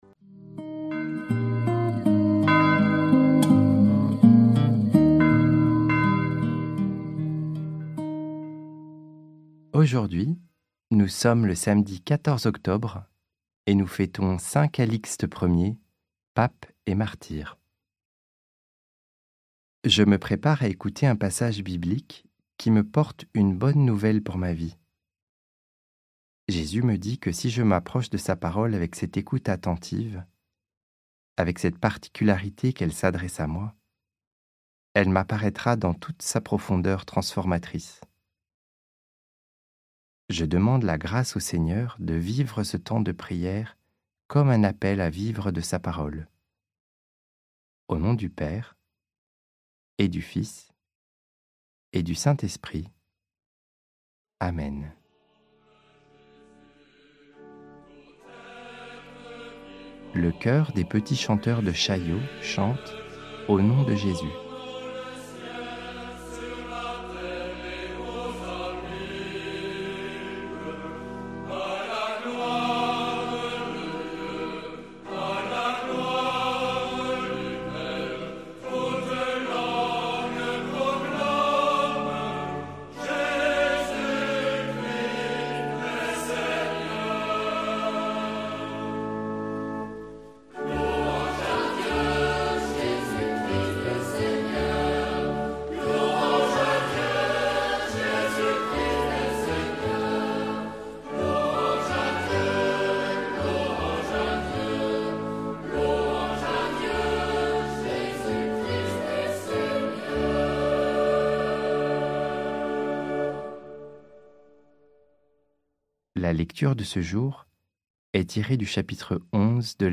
Prière audio avec l'évangile du jour - Prie en Chemin
Musiques